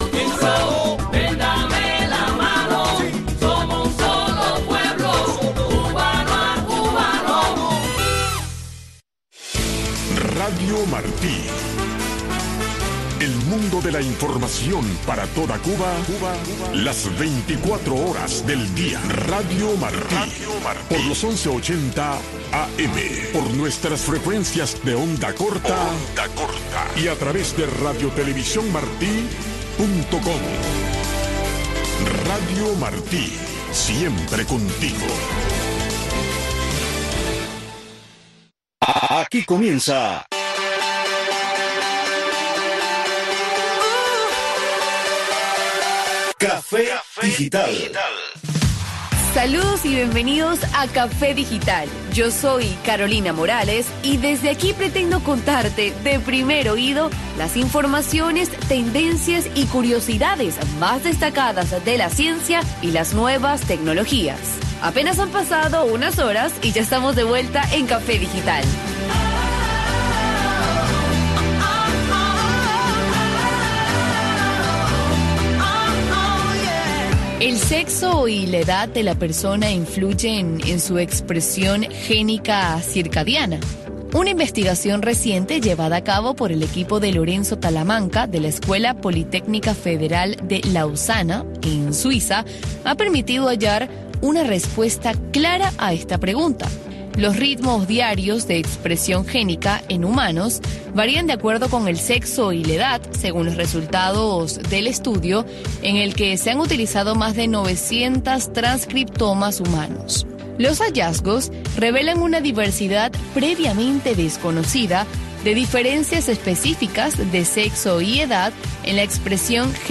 Noticiero de Radio Martí 5:00 PM | Segunda media hora